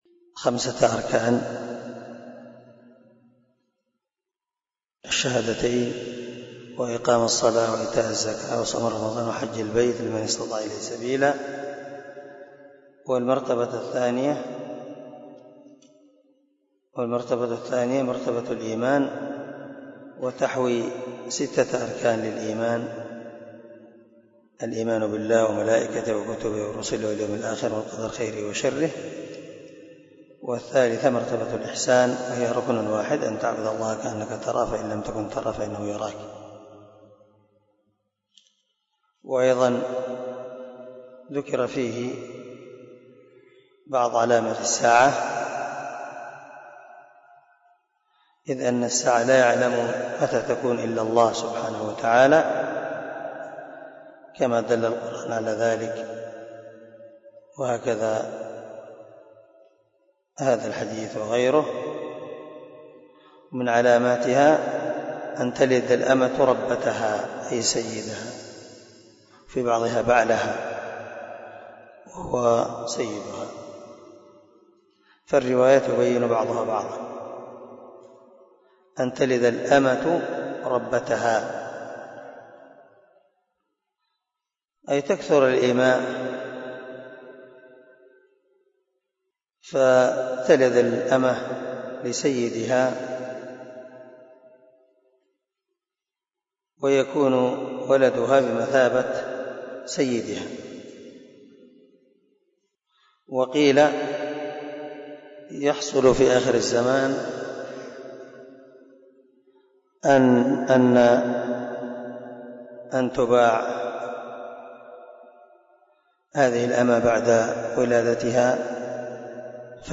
🔊 الدرس 31 من شرح الأصول الثلاثة